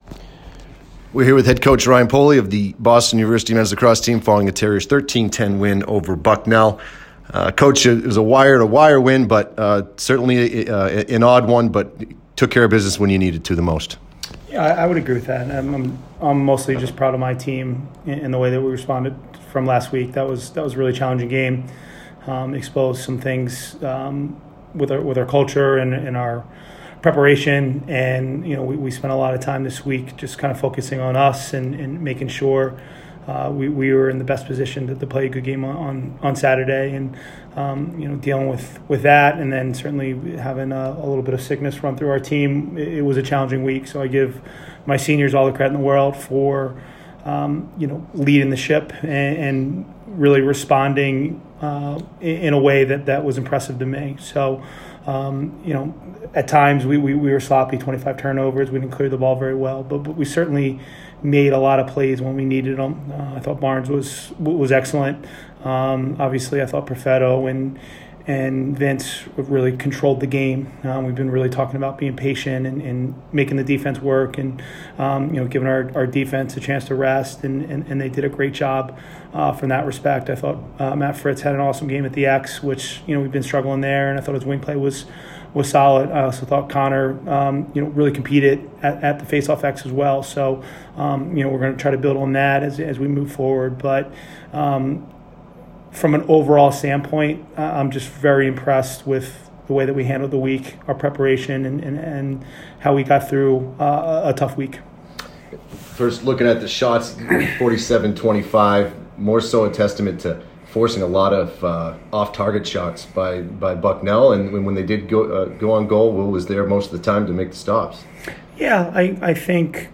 Bucknell Postgame Interview